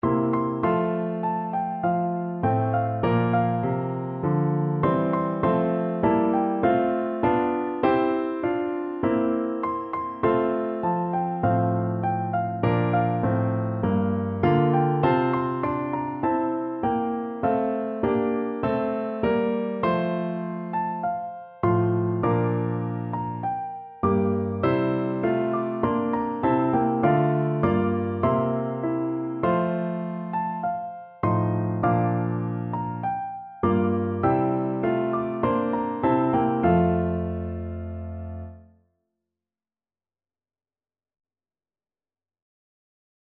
Free Sheet music for Piano Four Hands (Piano Duet)
4/4 (View more 4/4 Music)